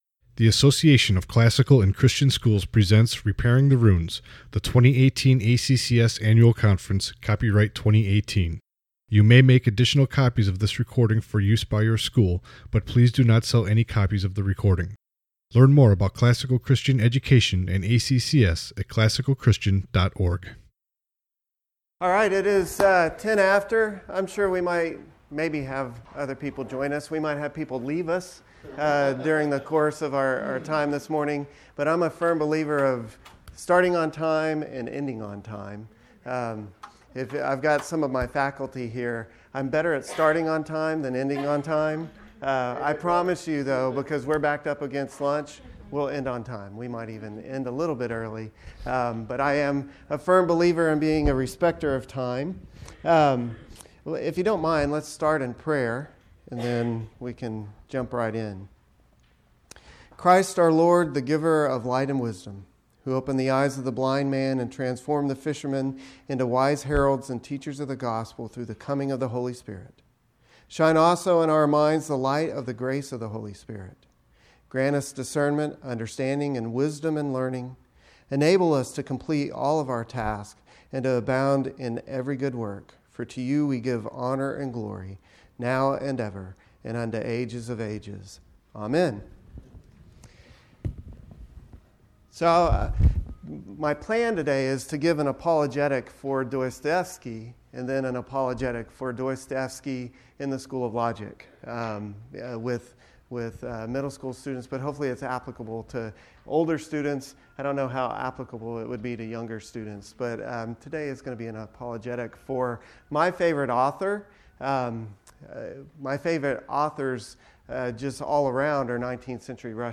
2018 Workshop Talk | 1:03:43 | 7-12, Literature